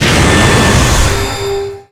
Cri d'Ultra-Necrozma dans Pokémon Ultra-Soleil et Ultra-Lune.